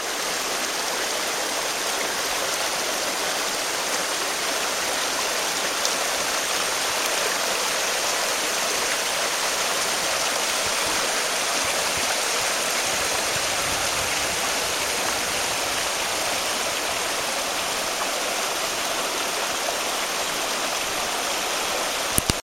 描述：i went to the Japan's keta river in summer 2005.This sound was recorded then .
标签： japan fieldrecording nature river water
声道立体声